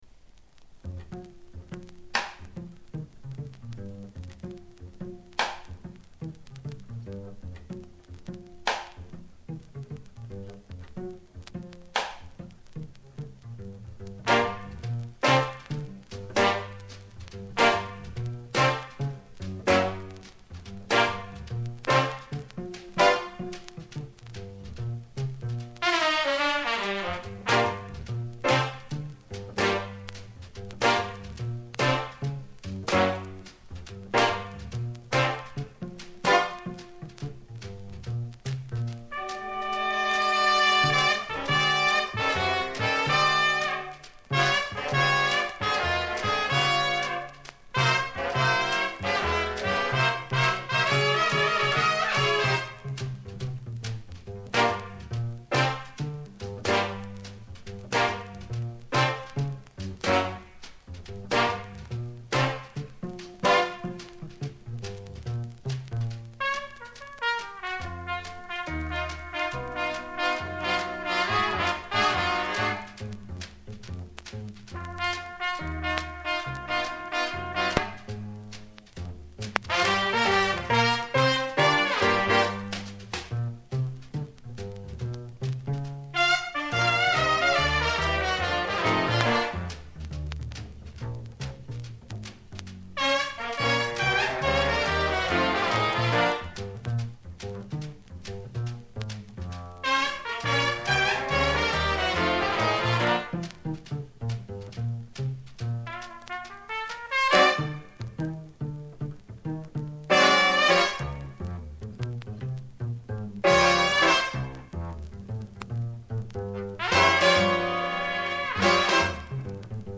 контрабас